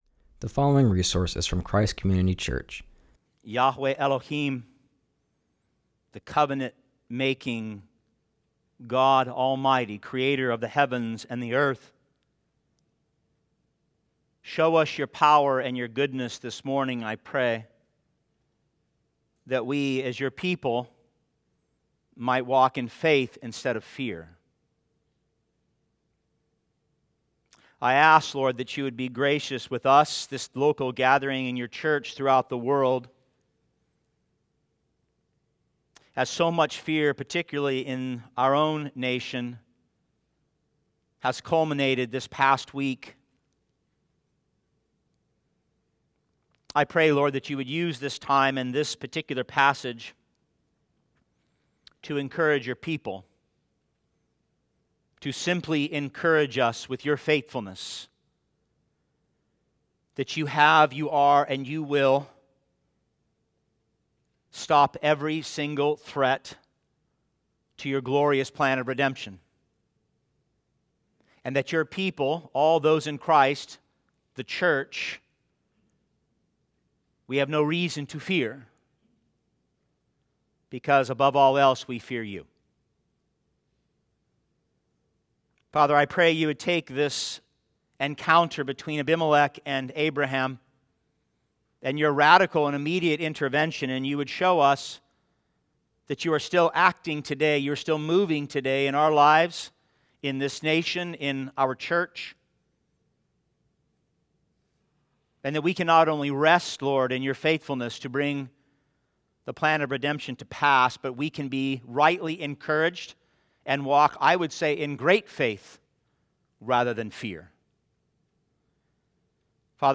continues our series and preaches from Genesis 20:1-18.